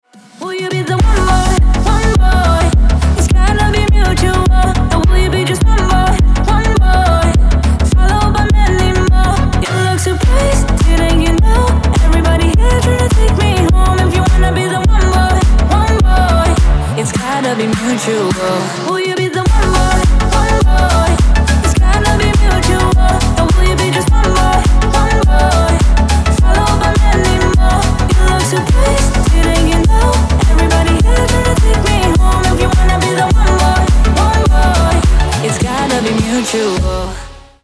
• Качество: 320, Stereo
deep house
женский голос
басы
Классный танцевальный трек